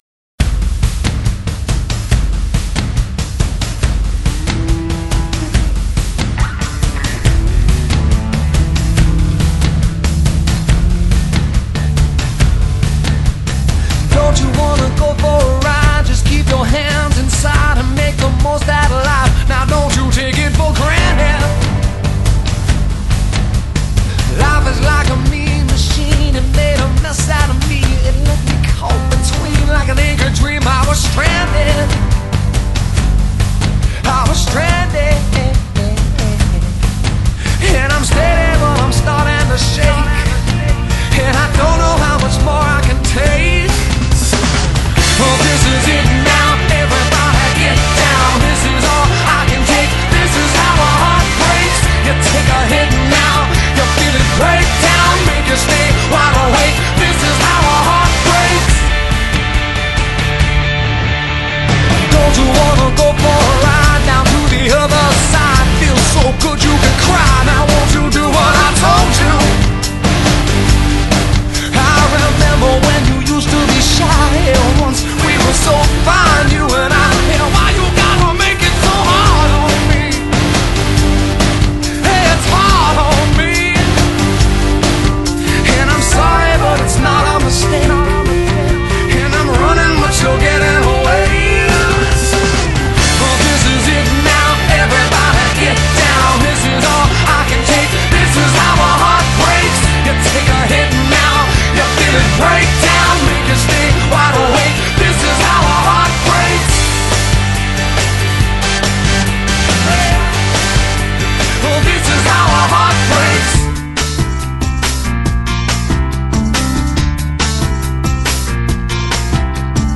职业：歌手